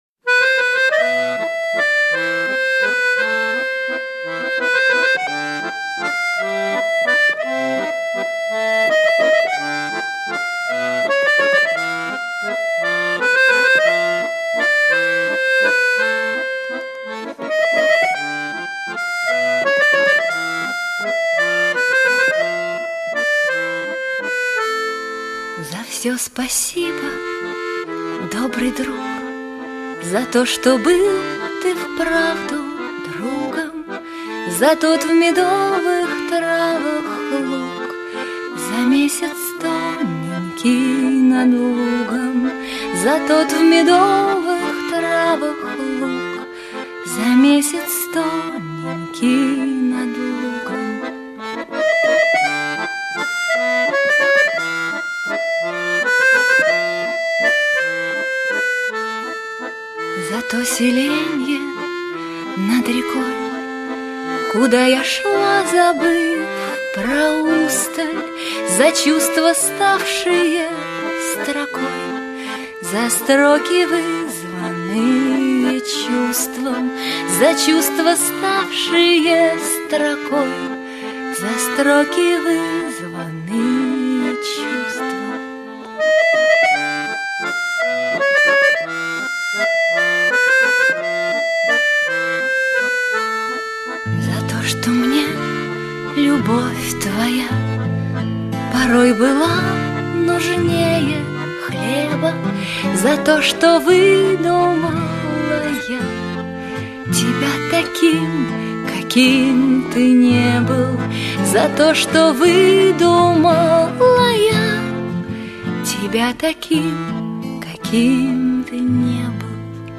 вальс